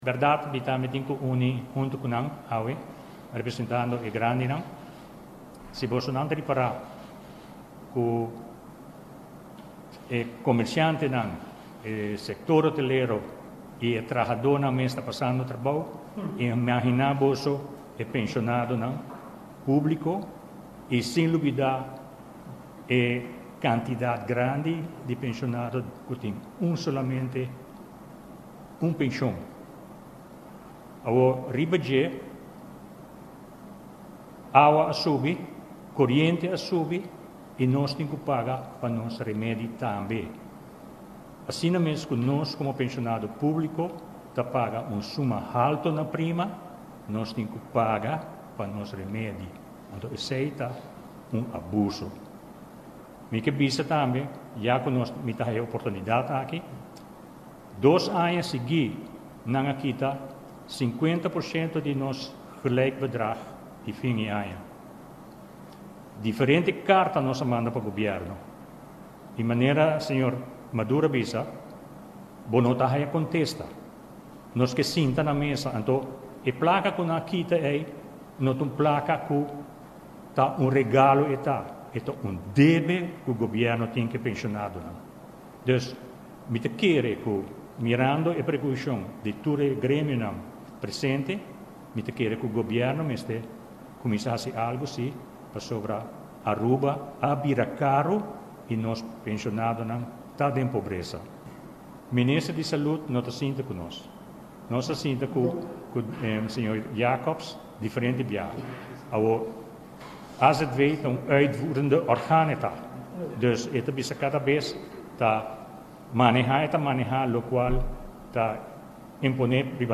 conferencia di prensa general di gremionan, sindicato y pensionadonan